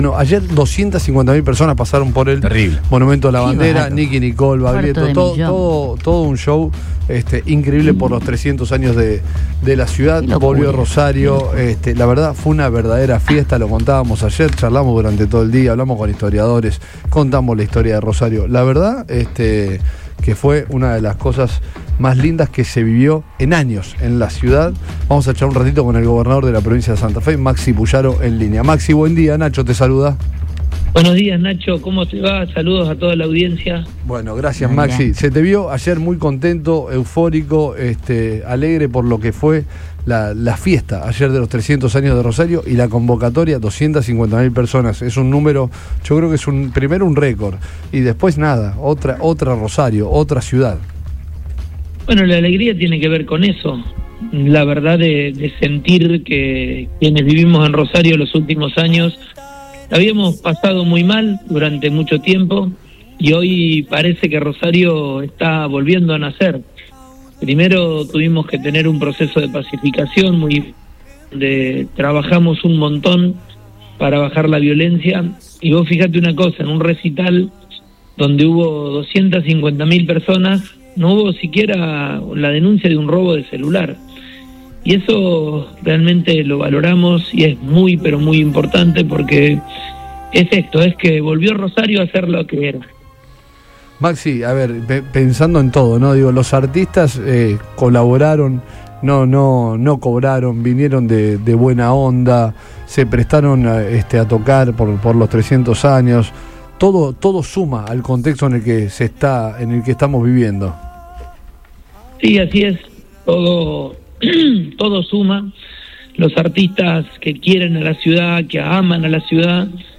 El gobernador de Santa Fe, Maximiliano Pullaro, habló en exclusiva con Todo Pasa por Radio Boing tras el multitudinario festejo por el tricentenario de Rosario…